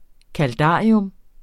Udtale [ kalˈdɑˀiɔm ]